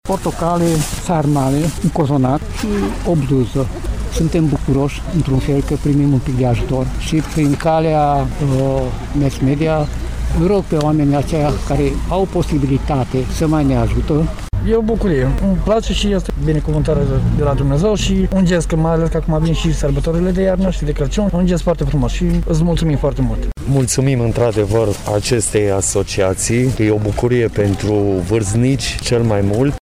Un parc din Târgu Mureș a găzduit astăzi un eveniment, inițiat de Asociația Solidaris, la care au fost invitate mai multe persoane nevoiașe care beneficiază de sprijin din partea Asociației.
Beneficiarii au primit alimente tradiționale de Crăciun precum și haine sau fructe și apreciază acest ajutor: